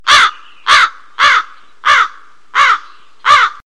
crow-sound.mp3